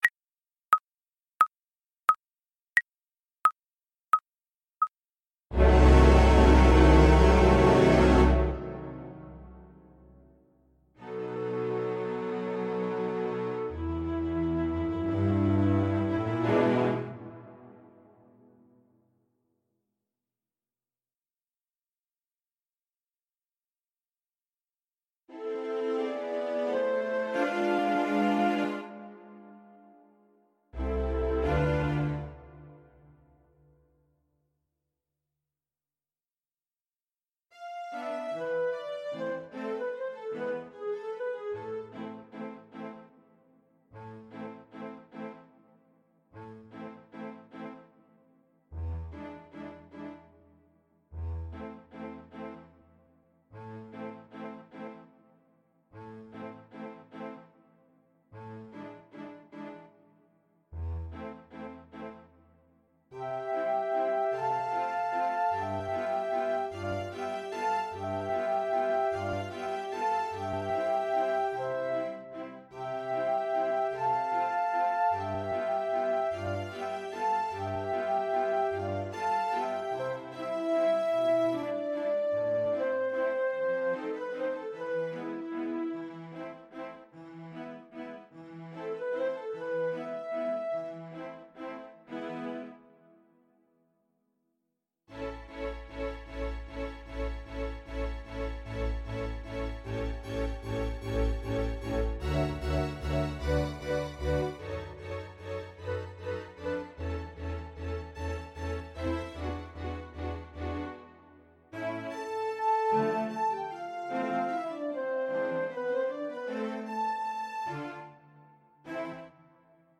Andantino =88 (View more music marked Andantino)
4/4 (View more 4/4 Music)
Classical (View more Classical Soprano Voice Music)